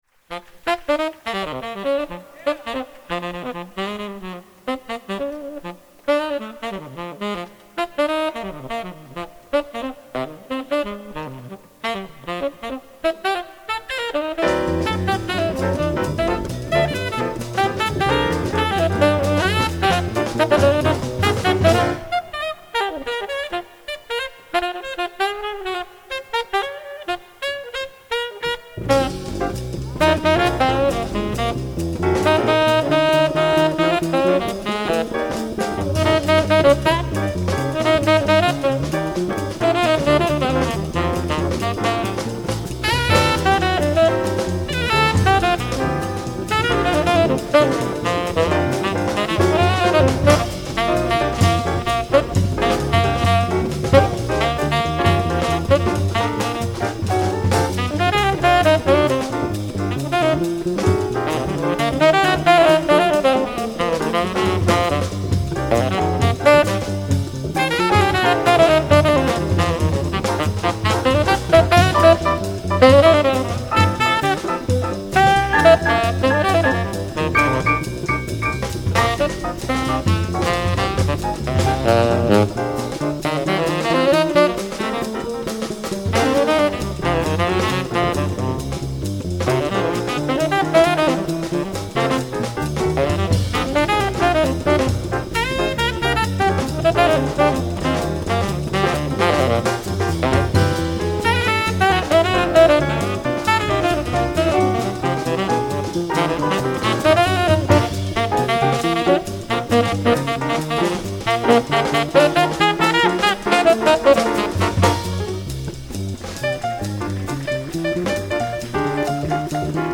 sax
pianoforte
contrabbasso
batteria
ProvenienzaAnzola Jazz Club Henghel Gualdi